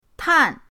tan4.mp3